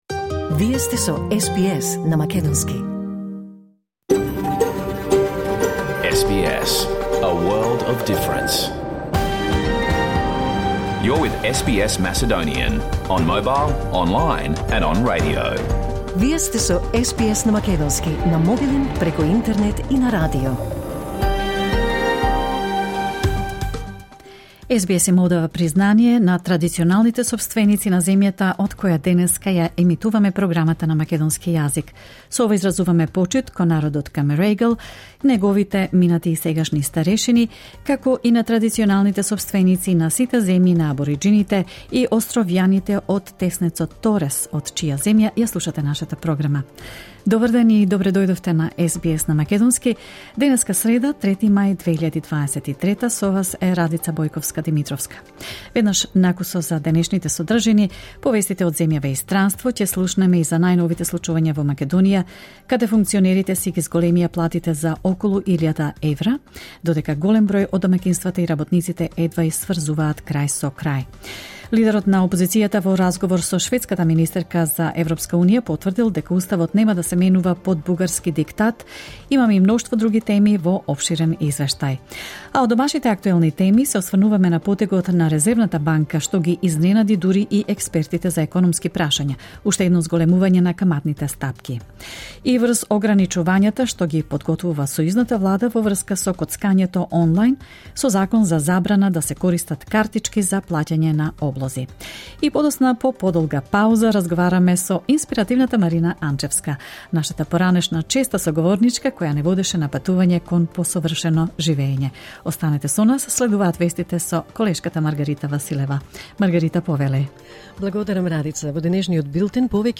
SBS Macedonian Live On Air 3 May 2023